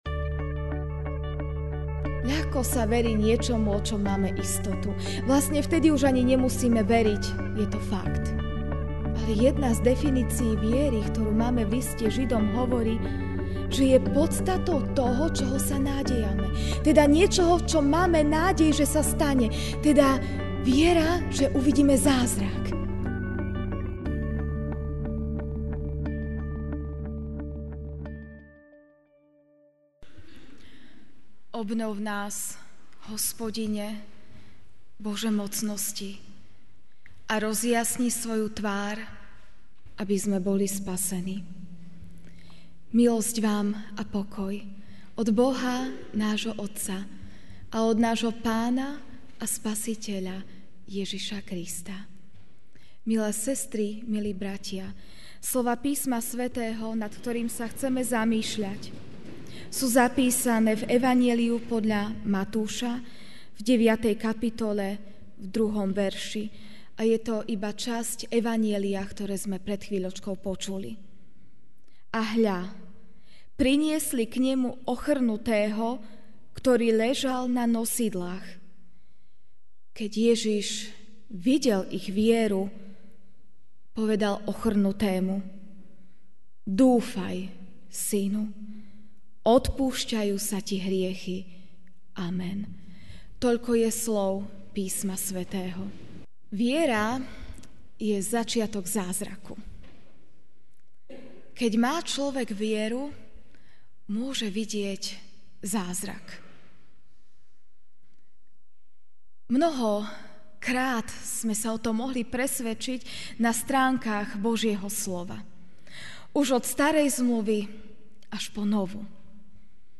Ranná kázeň: Sila viery (Mt 9, 2) A hľa, priniesli k Nemu ochrnutého, ktorý ležal na nosidlách.